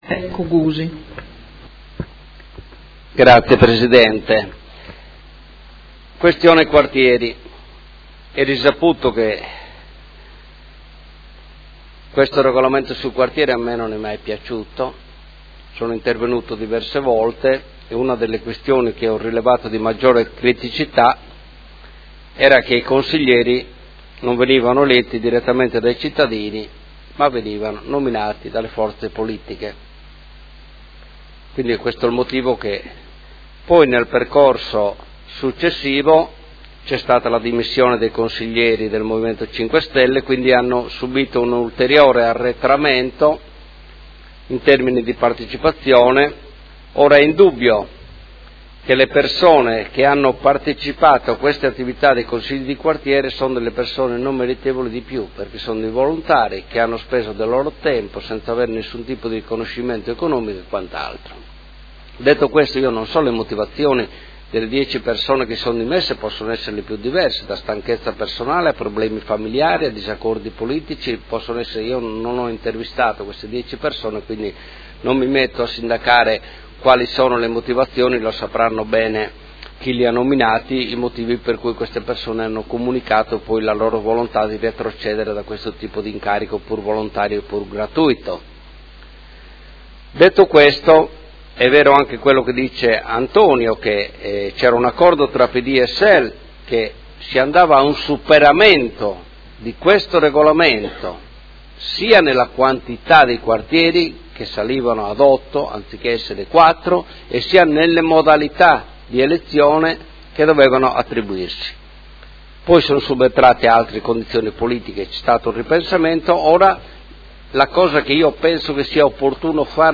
Seduta del 3/05/2018. Dibattito su proposta di deliberazione: Quartieri – Surrogazione di consiglieri dei Quartieri 1, 2, 3 e 4 e su Mozione presentata dal Gruppo Consiliare PD avente per oggetto: A sostegno dei quartieri e della loro azione presente e futura